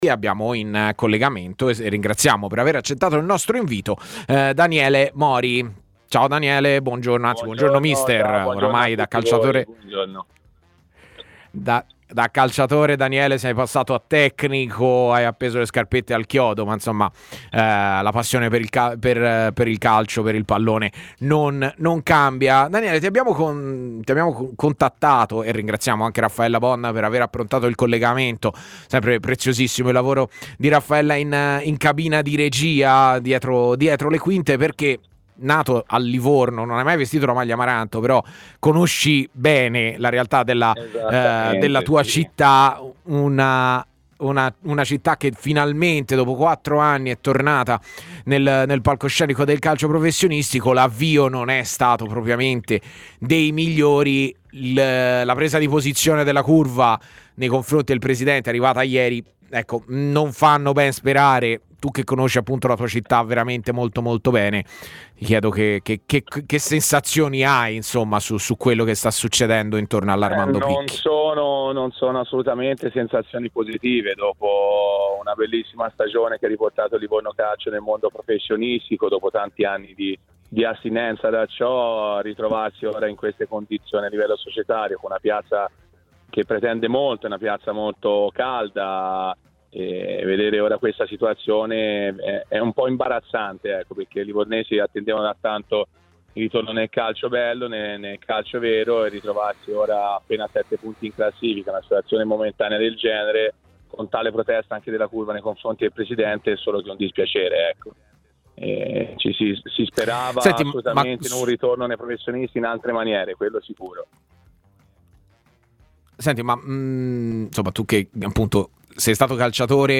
è intervenuto come ospite di 'A Tutta C', trasmissione in onda su TMW Radio e Il 61.